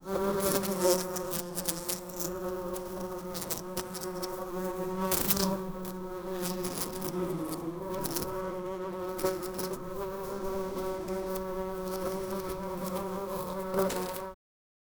bee colony creating an illusion to be from far away and then slowling coming near and then going far away agian.
bee-colony-creating-an-il-evsr2d6z.wav